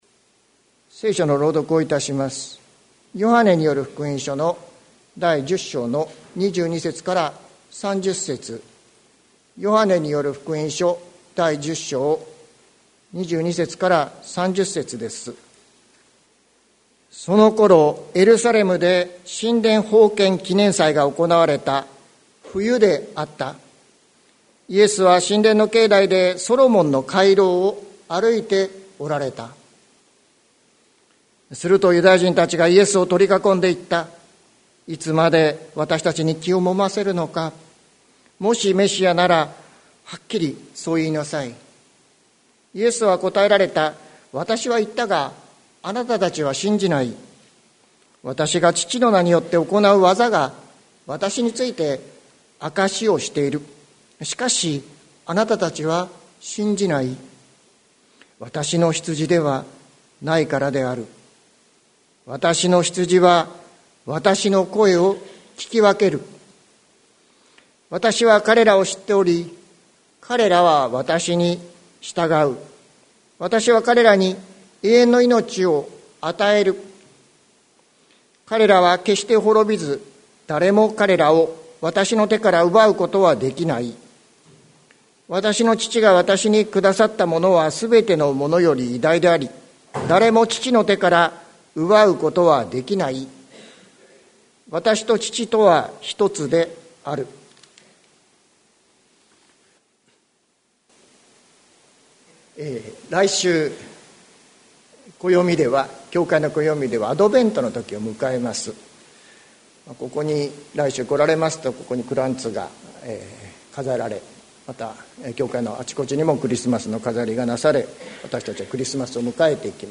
2022年11月21日朝の礼拝「わたしの羊と呼ばれて」関キリスト教会
説教アーカイブ。